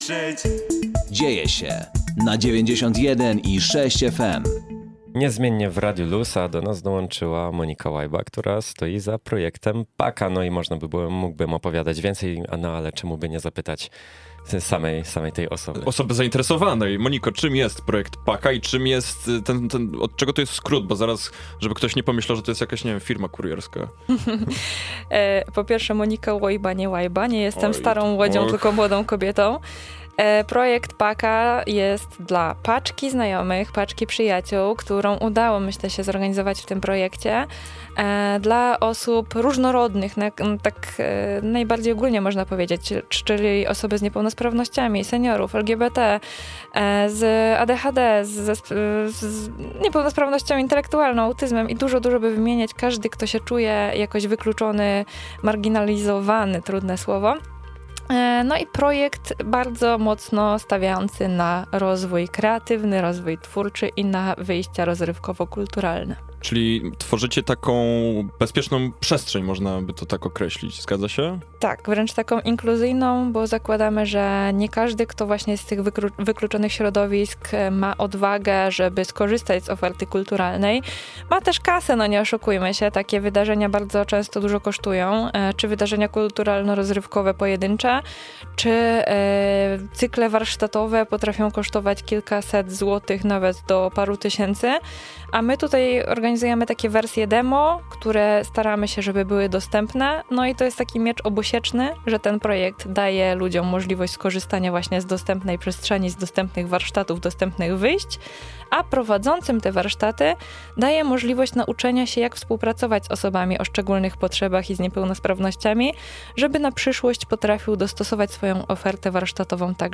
PA-KA – kreatywna społeczność, z której nikt cię nie wykluczy - Radio LUZ